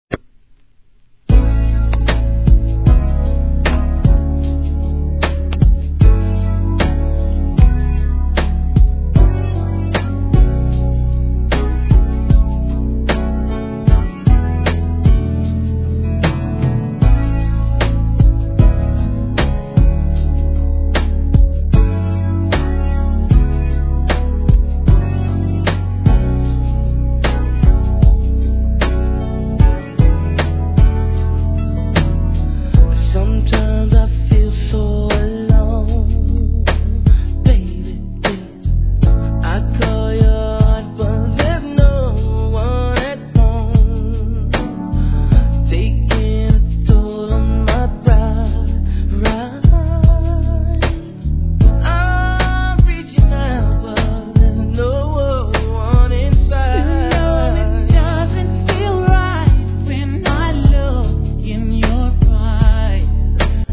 A-1の出だしでちょっとノイズあります。
HIP HOP/R&B